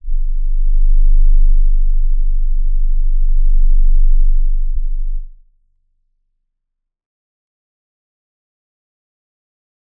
G_Crystal-G0-f.wav